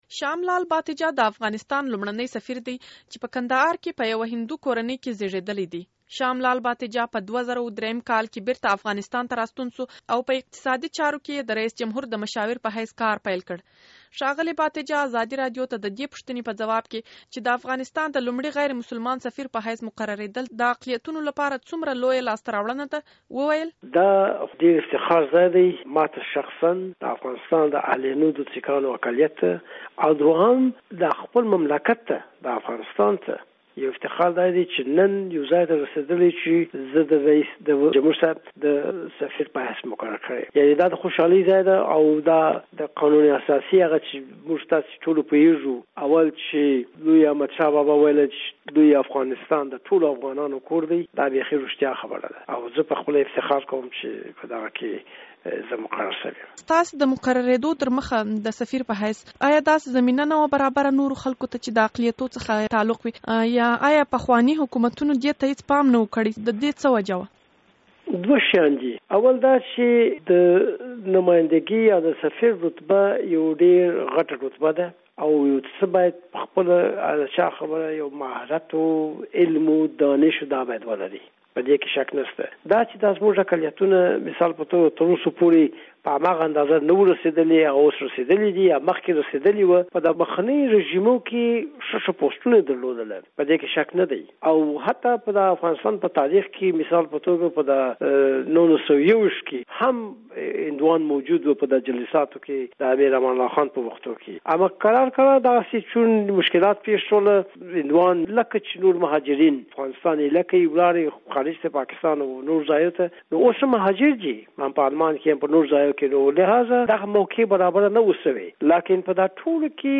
له شام لال باتېجه سره مرکه